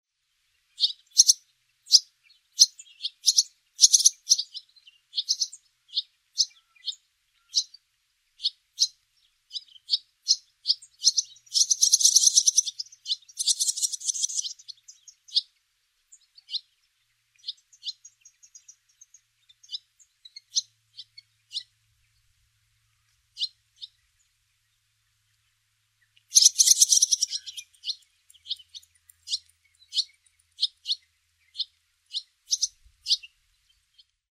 Barn Swallow  Hirundo rustica
Sonogram of Barn Swallow calls
Knockentiber-Springside disused railway line, Ayrshire, Scotland  1 September 2014
Calls from a fledged brood being fed in mixed farmland.